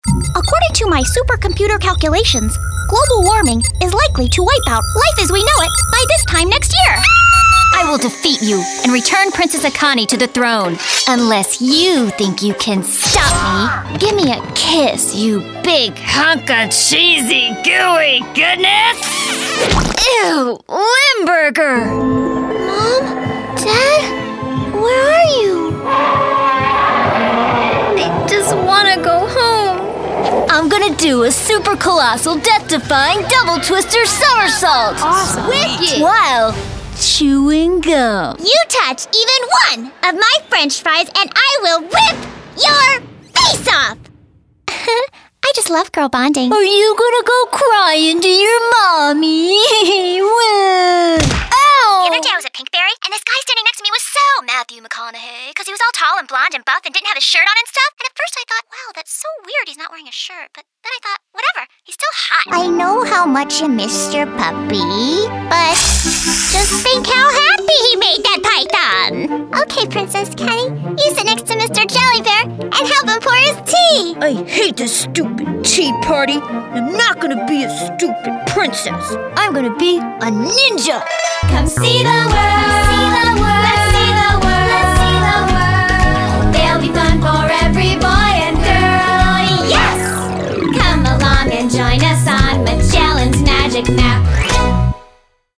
Child, Teenager, Young Adult, Adult
Voice range: kids (girl/boy), teen, and adult female.
Voice quality: Youthful, vibrant, energetic, quirky, sincere, genuine, sweet, deadpan.
ANIMATION 🎬